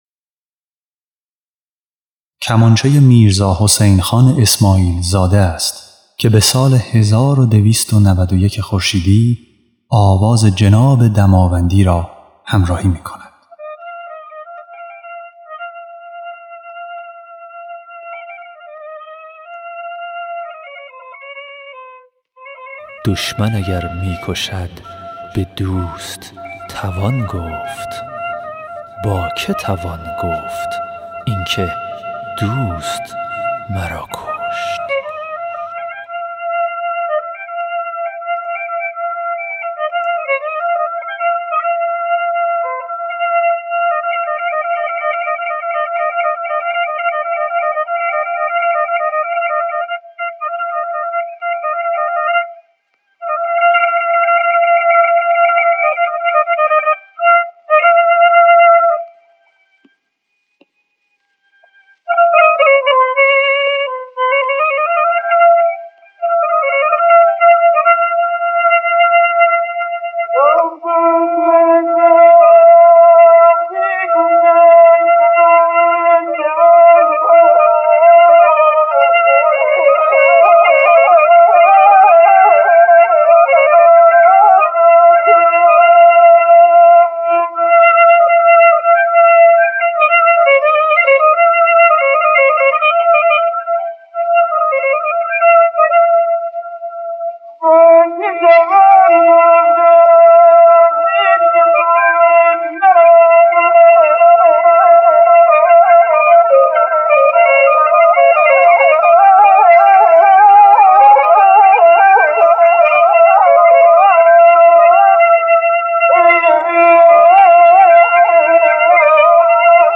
خواننده
نوازنده کمانچه